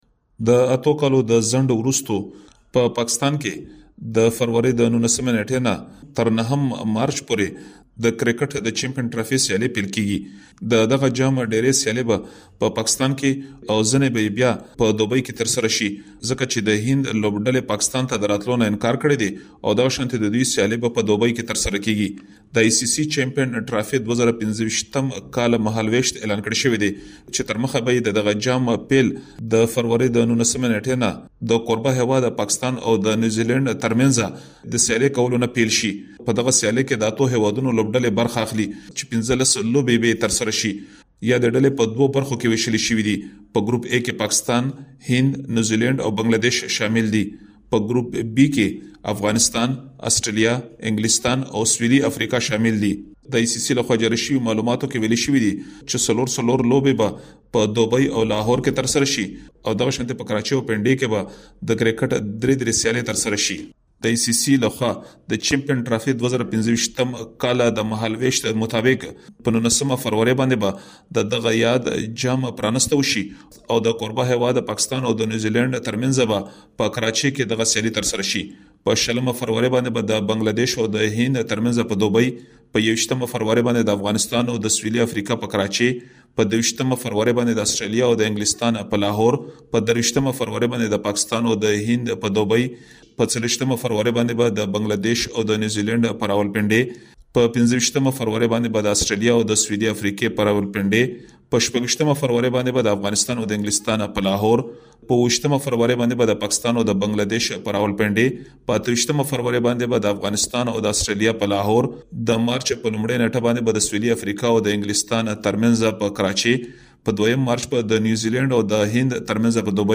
یو رپوټ